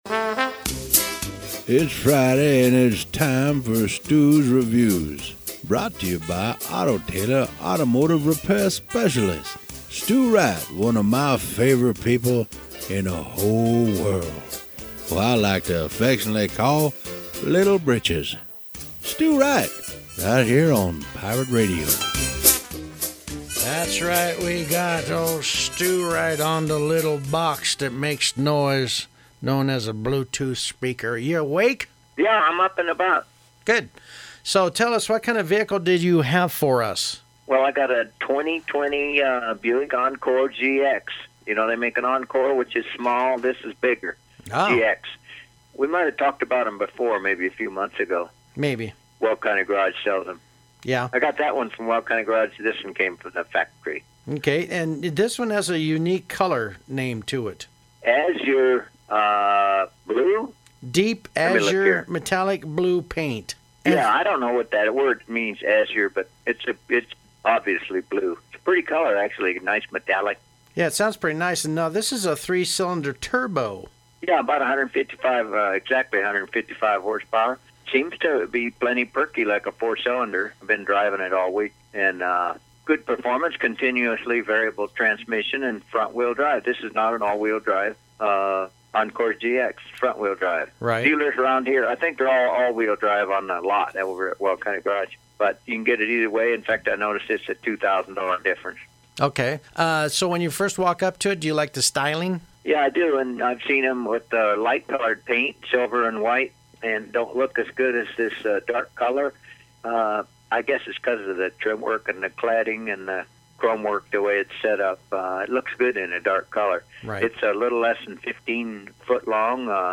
Radio Review: